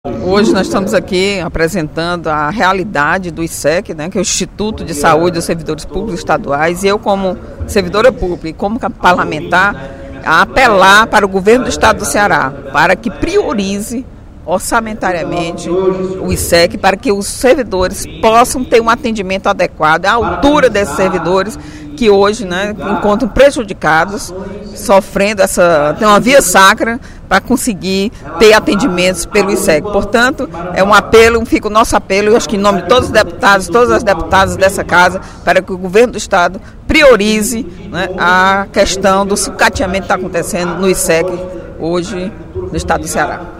A deputada Eliane Novais (PSB) fez um apelo nesta sexta-feira (01/03), durante o primeiro expediente da sessão plenária, para que Governo do Estado adote soluções rápidas e concretas para resolver os problemas enfrentados pelos servidores para obter atendimento no Instituto de Saúde dos Servidores do Estado do Ceará (Issec).